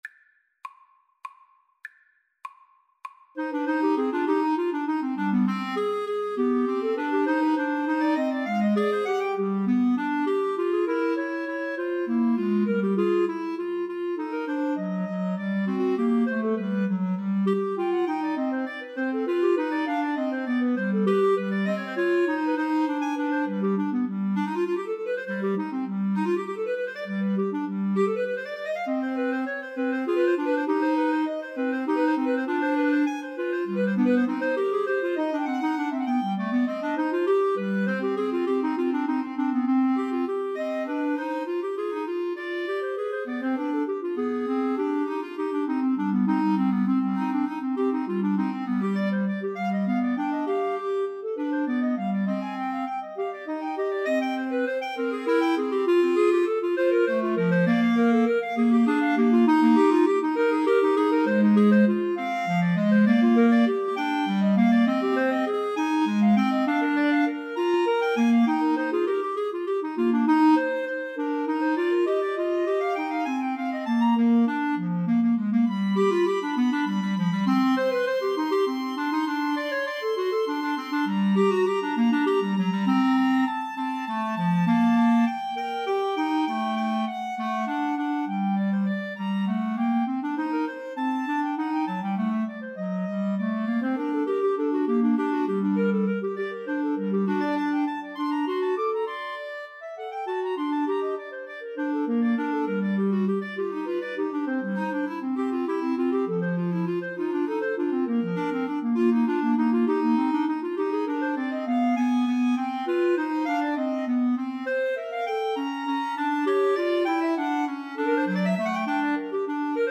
Free Sheet music for Clarinet Trio
Allegro (View more music marked Allegro)
3/4 (View more 3/4 Music)
C minor (Sounding Pitch) D minor (Clarinet in Bb) (View more C minor Music for Clarinet Trio )
Clarinet Trio  (View more Advanced Clarinet Trio Music)
Classical (View more Classical Clarinet Trio Music)